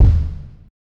Kick (French!).wav